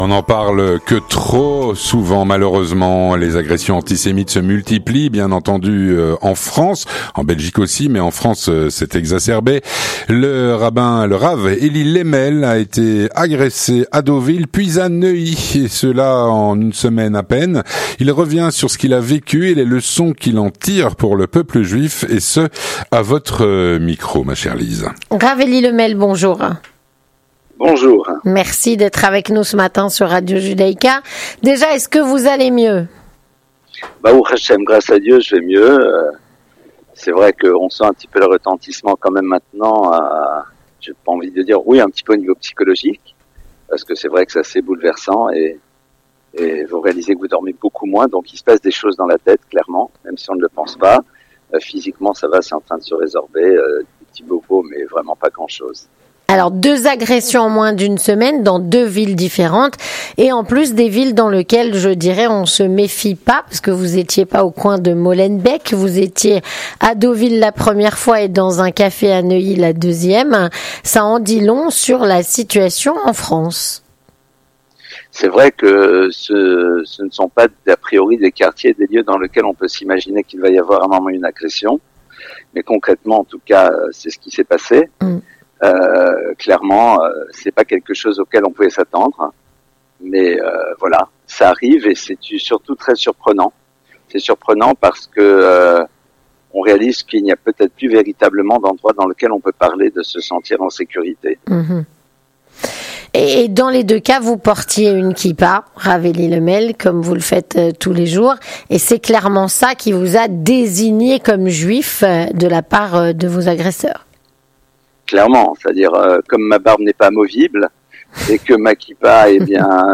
Rencontre - Agressions antisémites en France.